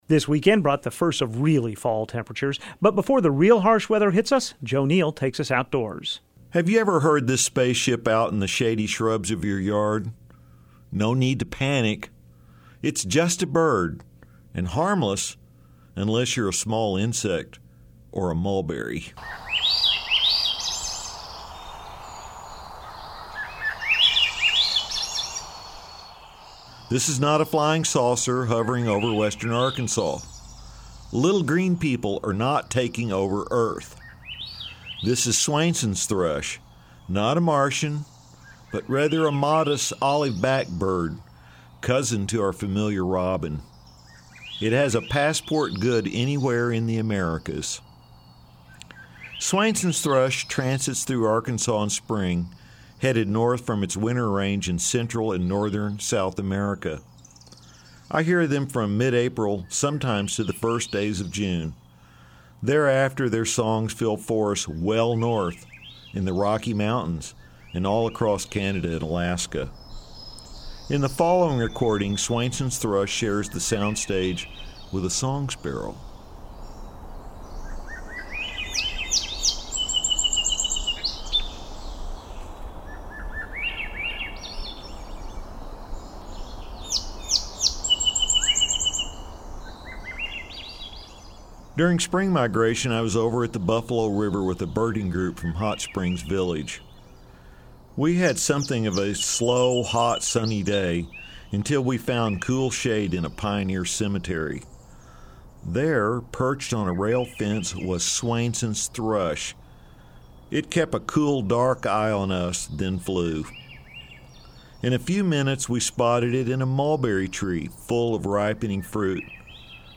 the otherworldly sound the thrush